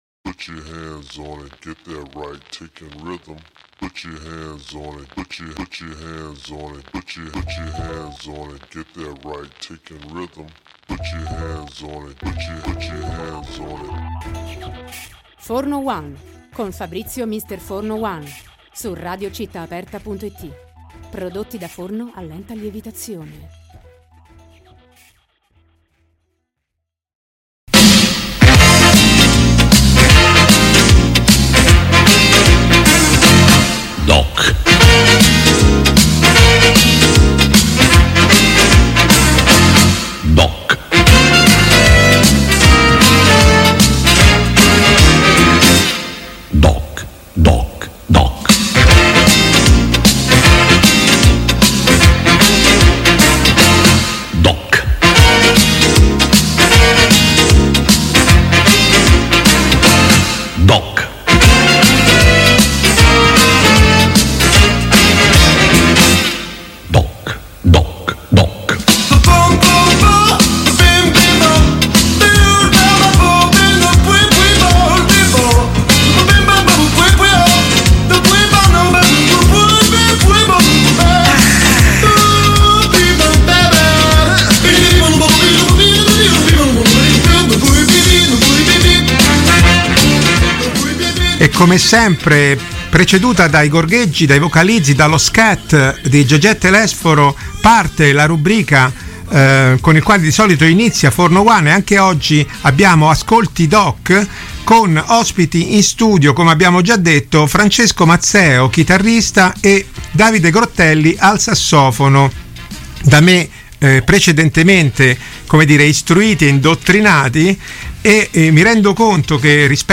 Ascolti DOC: MaGro Duo – intervista e minilive
Il duo si è poi reso protagonista di un minilive